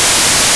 The files are for speech plus noise, and only noise.